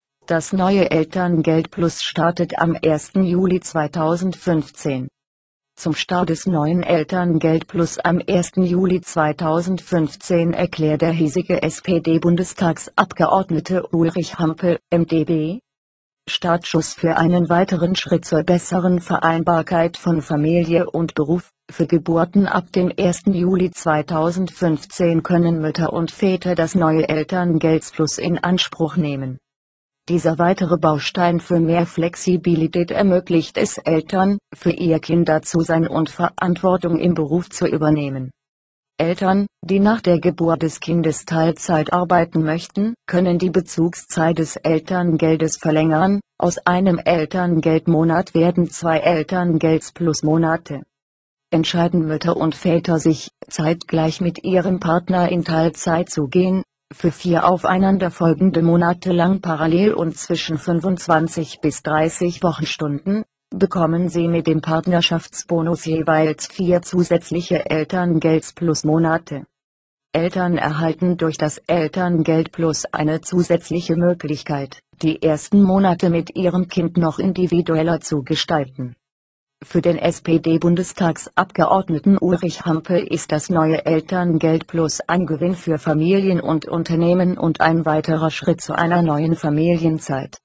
Zum Start des neuen ElterngeldPlus am 1. Juli 2015 erklärt der hiesige SPD-Bundestagsabgeordnete Ulrich Hampel, MdB: